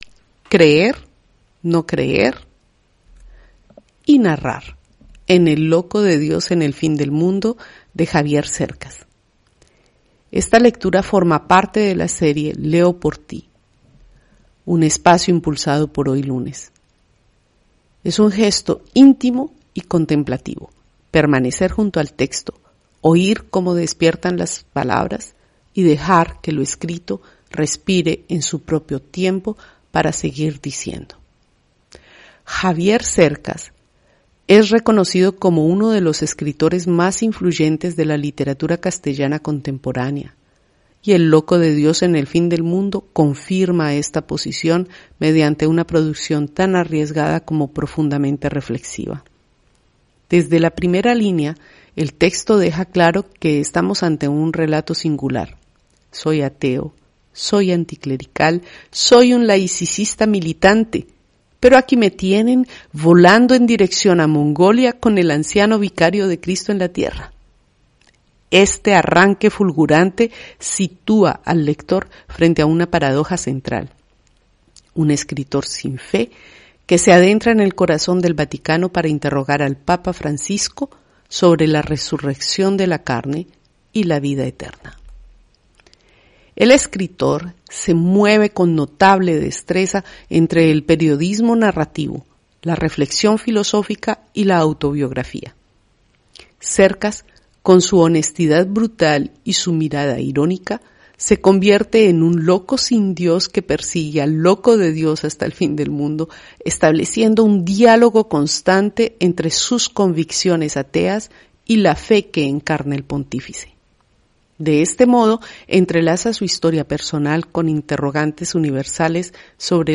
HoyLunes – Esta lectura forma parte de la serie Leo por ti, un espacio impulsado por ‘HoyLunes’. Es un gesto íntimo y contemplativo: permanecer junto al texto, oír cómo despiertan las palabras y dejar que lo escrito respire en su propio tiempo, para seguir diciendo.